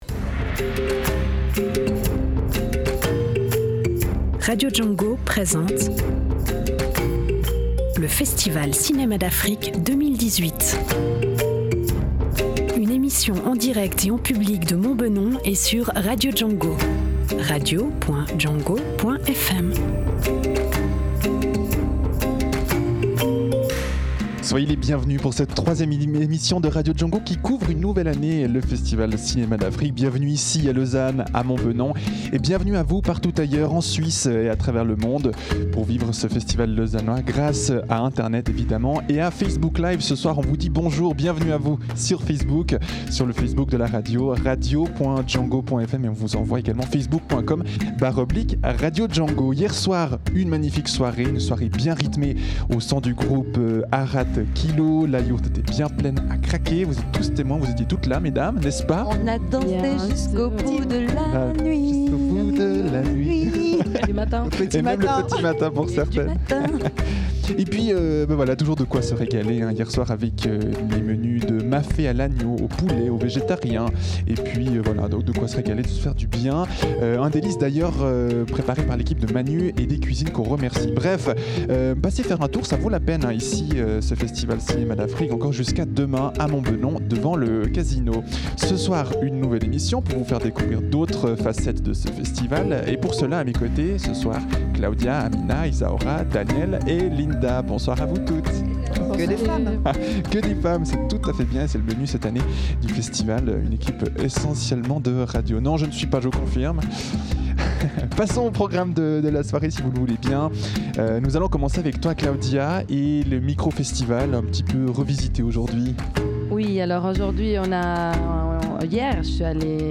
19h – 20h30, en direct et en public,